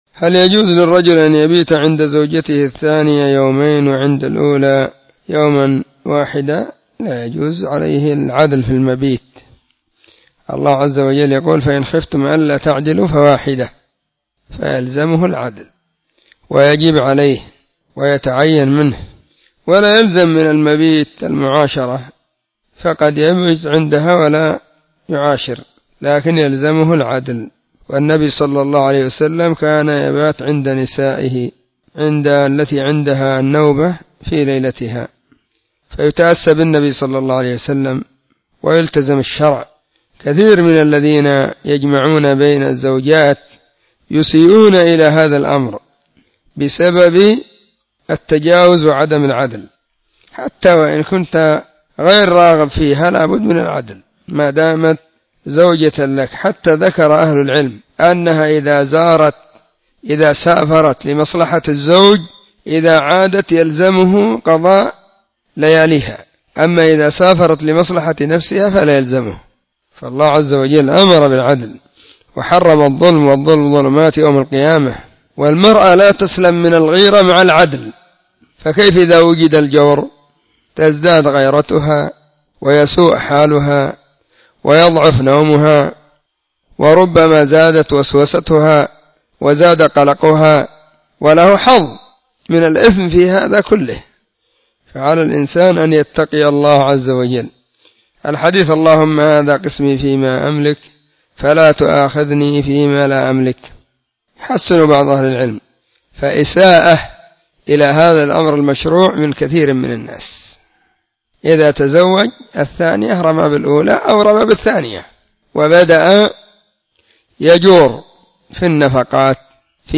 📢مسجد – الصحابة – بالغيضة – المهرة، اليمن حرسها الله.